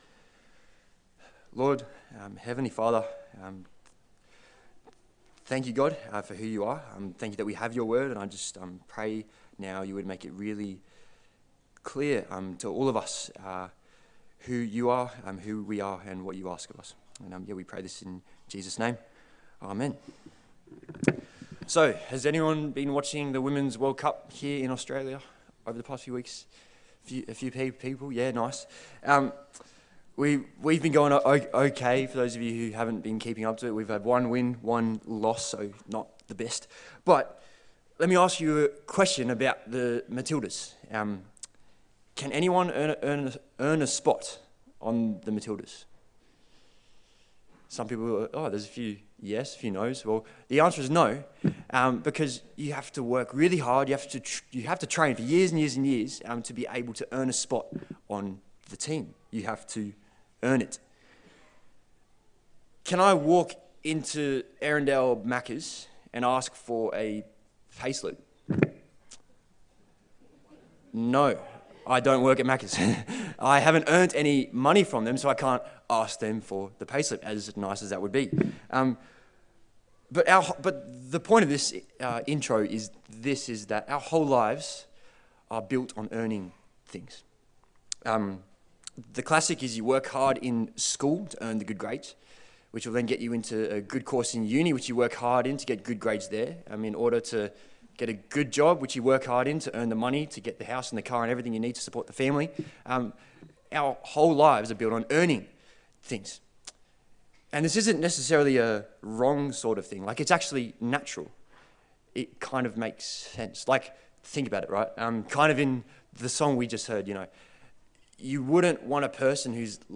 Galatians Passage: Galatians 2:15-21 Service Type: Sunday Service